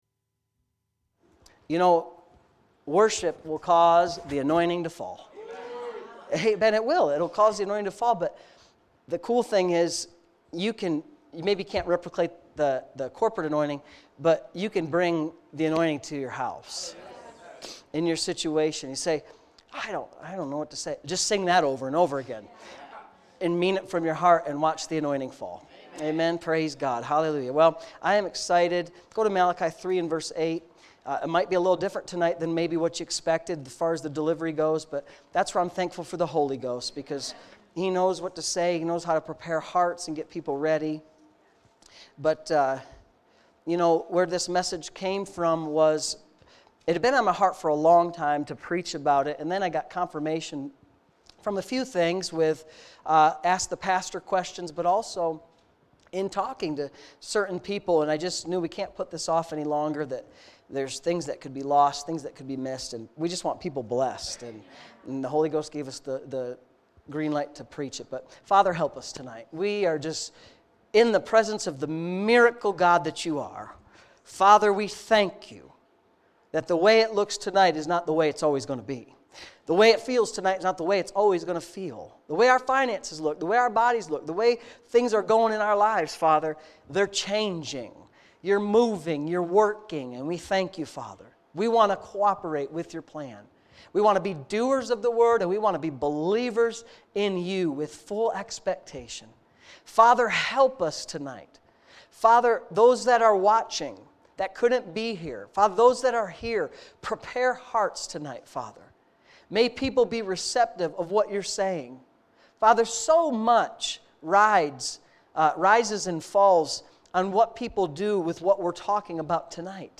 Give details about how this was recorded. Wednesday Evening Services